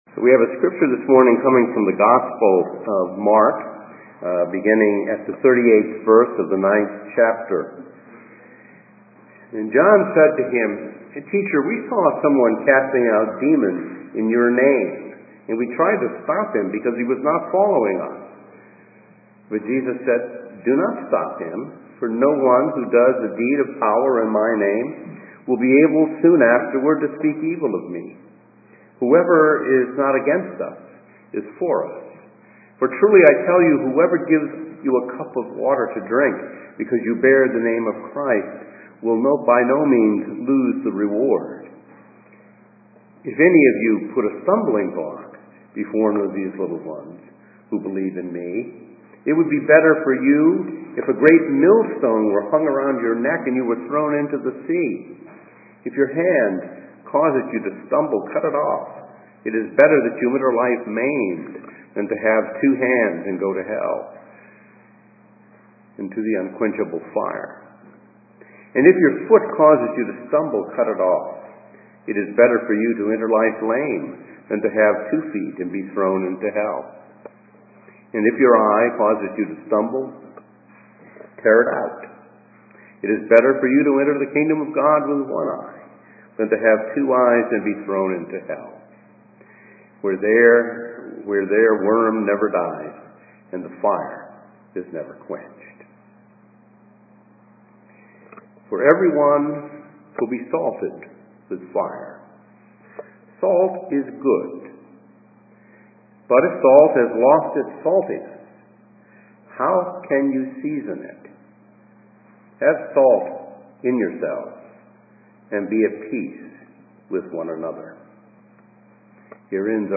A message from the series "Lectionary."